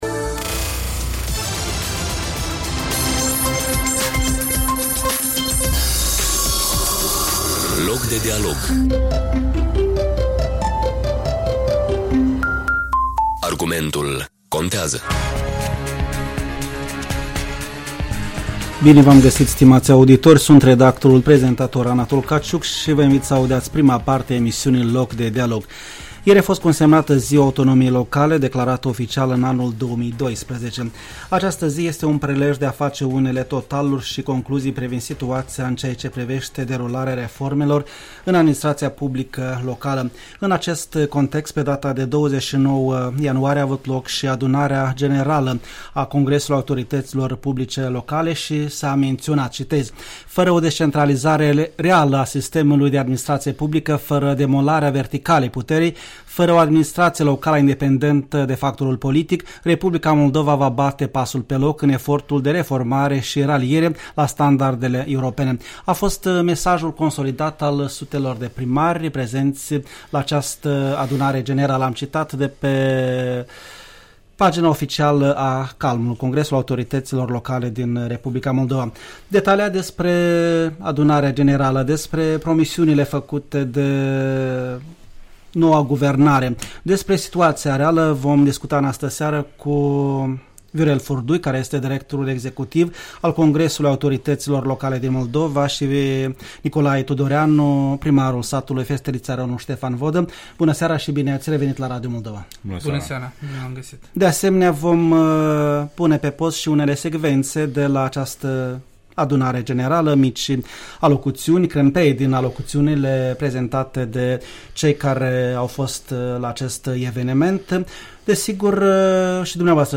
În studio